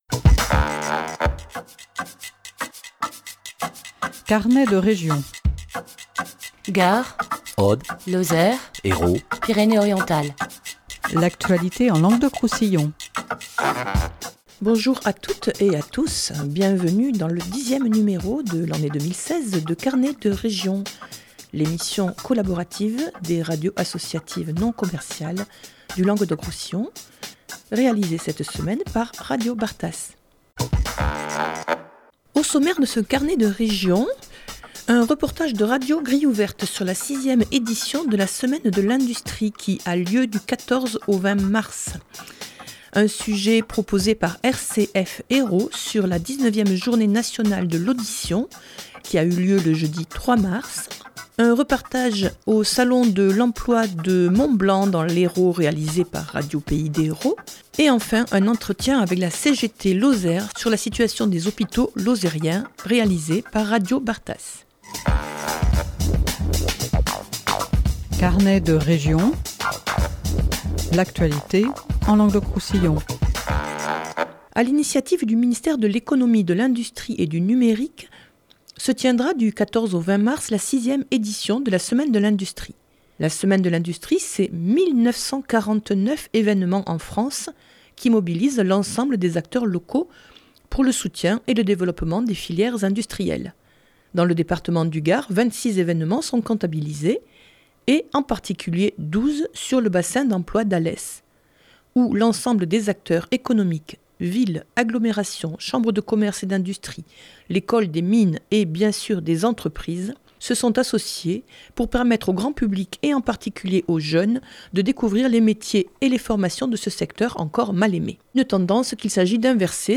Un reportage de radio Grille ouvert sur la sixième édition de la Semaine de l’Industrie qui a lieu du 14 au 20 mars